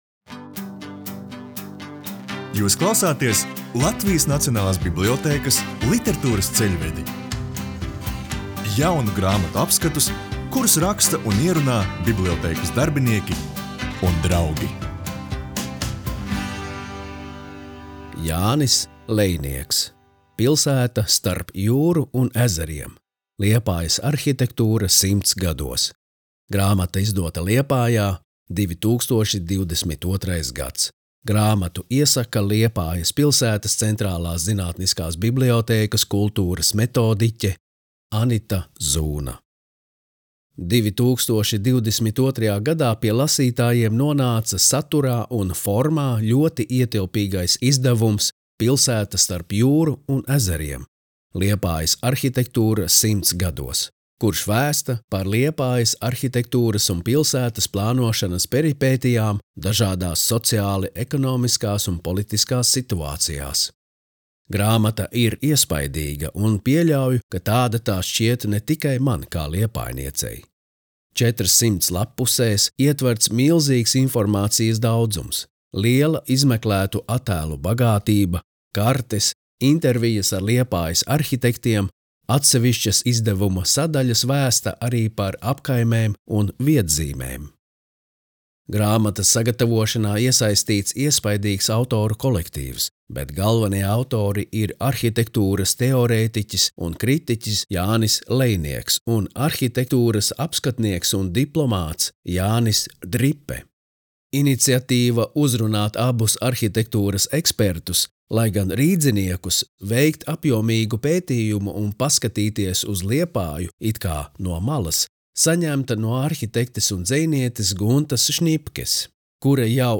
skaņu režisors, izpildītājs
Latvijas Nacionālās bibliotēkas audio studijas ieraksti (Kolekcija)